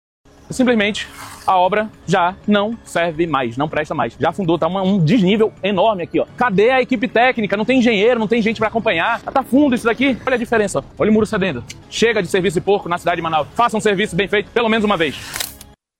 O vereador Rodrigo Guedes relata que a situação pode ser agravada e rua pode ceder.
SONORA-RODRIGO-GUEDES-OK.mp3